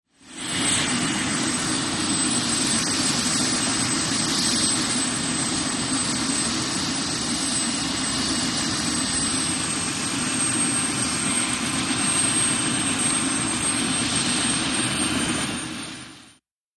HELICOPTER JET: EXT: Idle. Good jet engine wine.